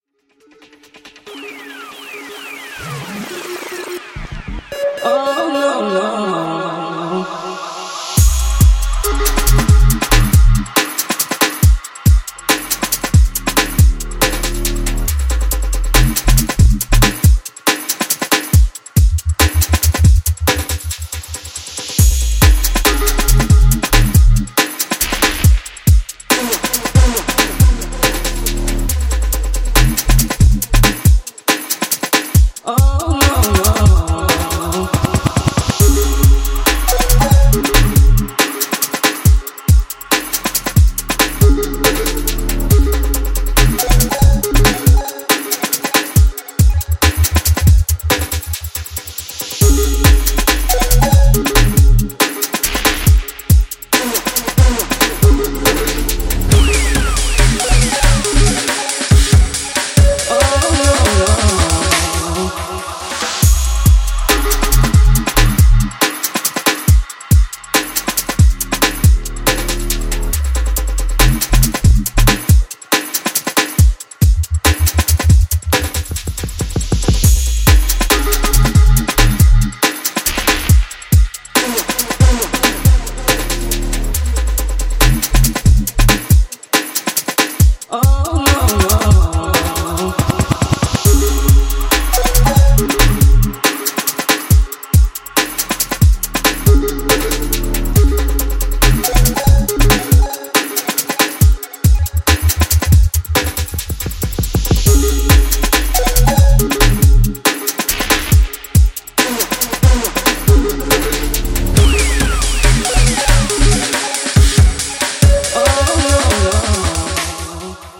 UKG
London based producer